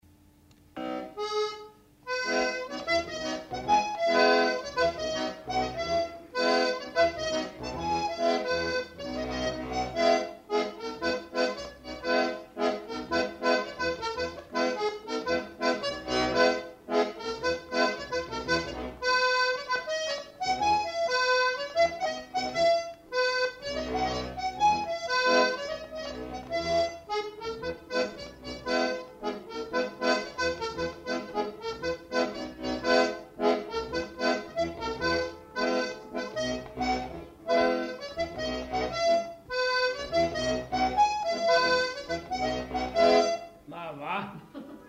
Aire culturelle : Savès
Lieu : Pavie
Genre : morceau instrumental
Instrument de musique : accordéon diatonique
Danse : rondeau